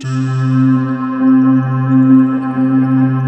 Index of /90_sSampleCDs/USB Soundscan vol.28 - Choir Acoustic & Synth [AKAI] 1CD/Partition C/16-NIMBUSSE